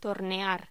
Locución: Tornear